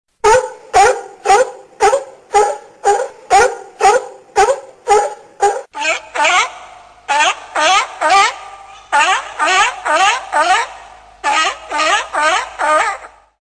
Kategorien Tierstimmen